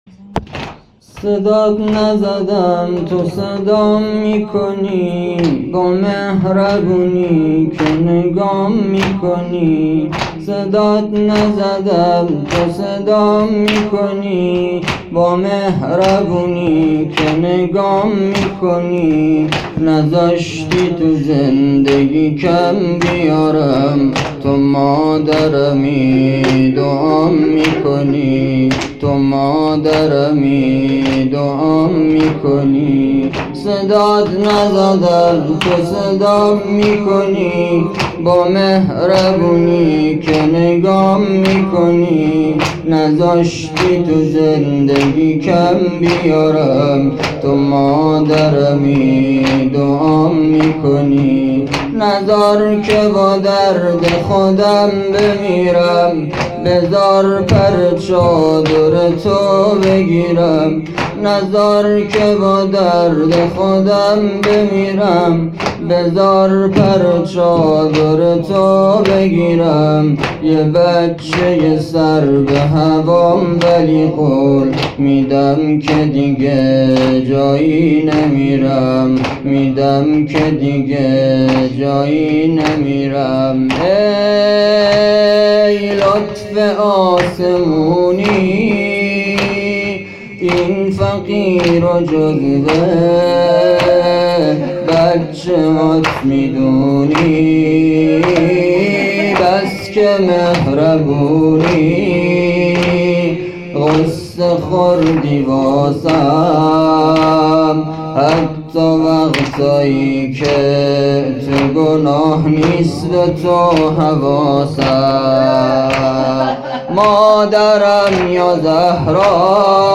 فاطمیه 1401